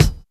WU_BD_060.wav